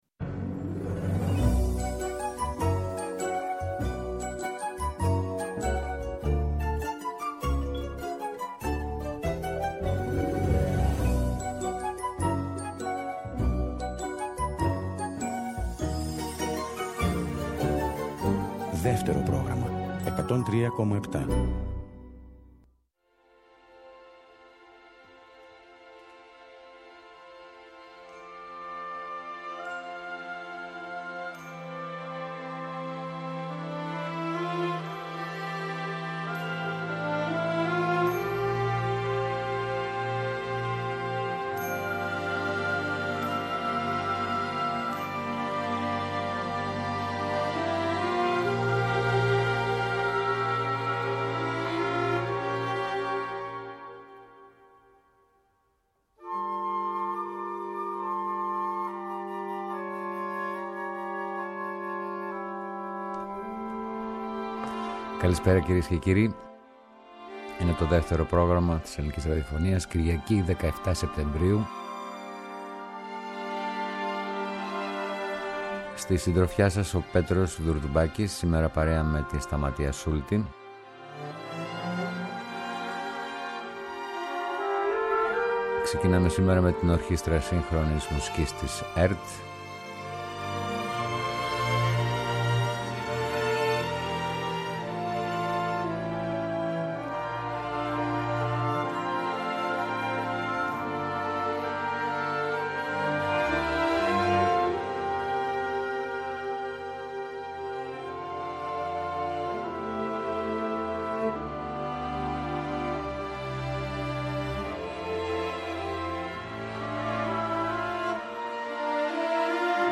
Εκπομπές Μουσική